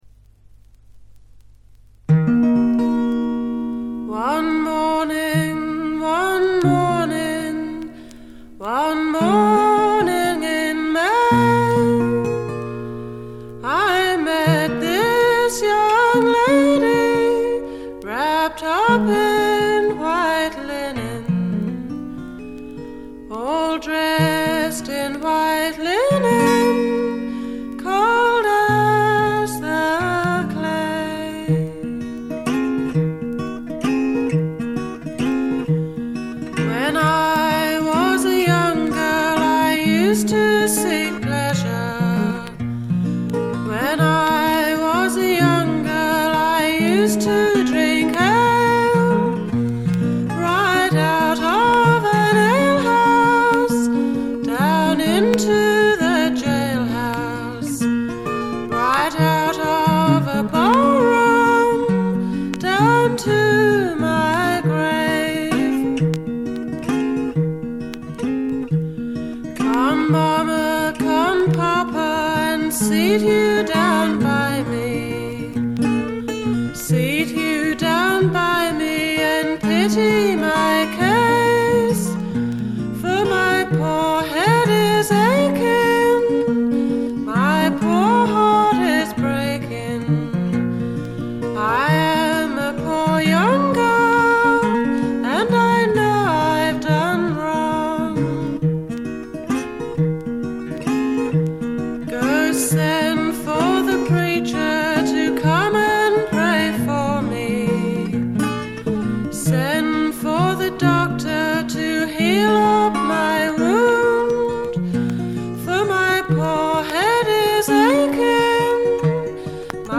バックグラウンドノイズ、軽微なチリプチが聞かれはしますがほとんど気にならないレベルと思います。
試聴曲は現品からの取り込み音源です。